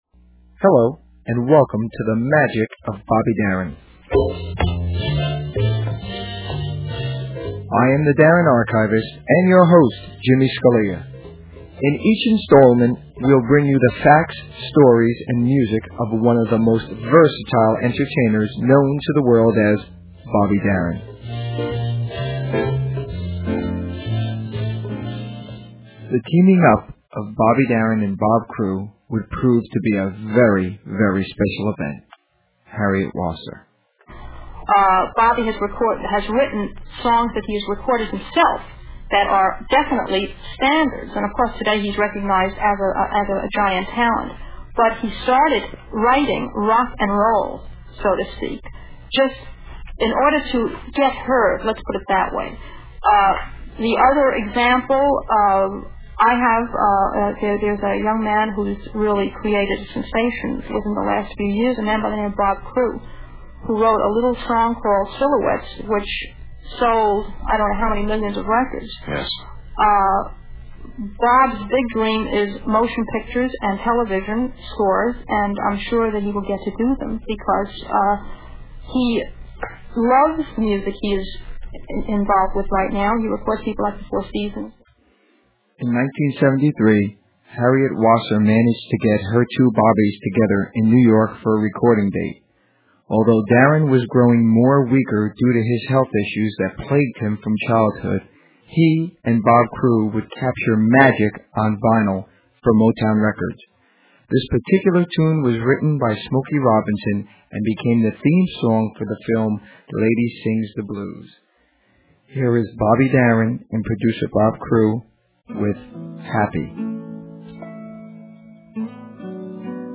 Technical Note: Please keep in mind that due to time and space contraint on the internet, and legal worries of sharing too much, the music portions have been edited and the quality is subpar.